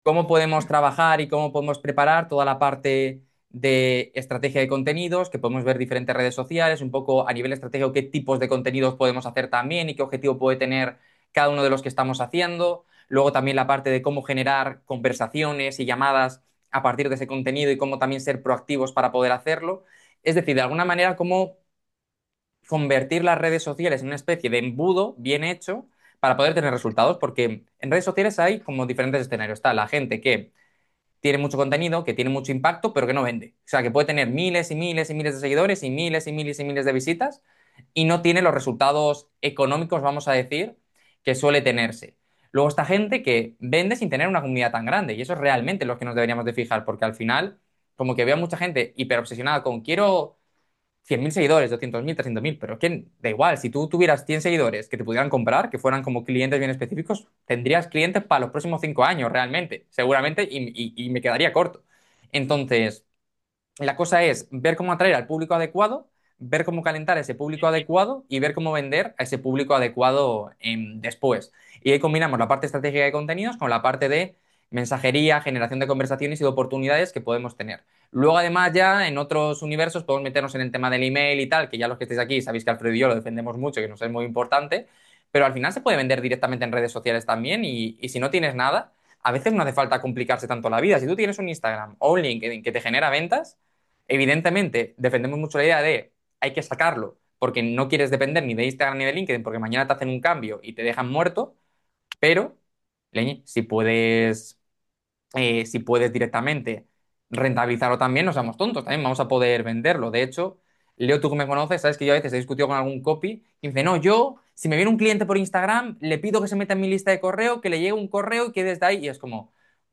¿Es posible vender sin email? ¿Es posible montar un embudo solo en redes sociales? Lo analizamos en directo.